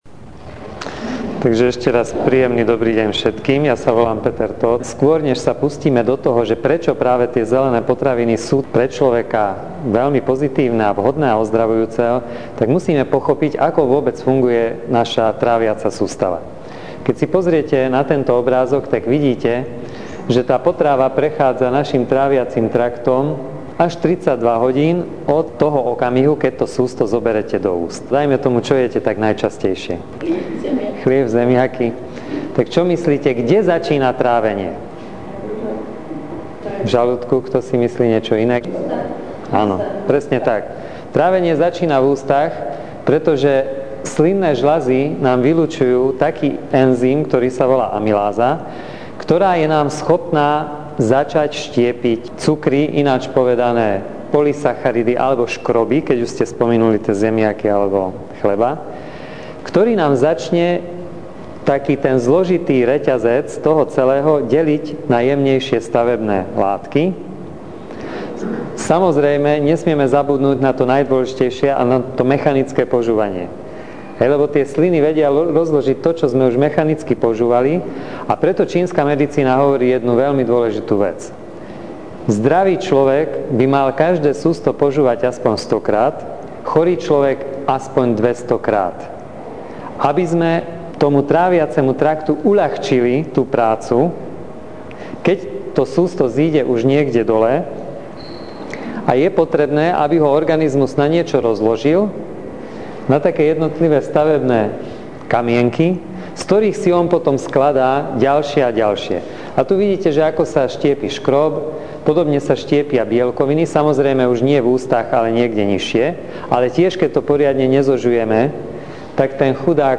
K dispozícii na stiahnutie sú aj prednášky vo formáte mp3.